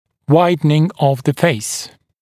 [‘waɪdnɪŋ əv ðə feɪs][‘уайднин ов зэ фэйс]расширение лица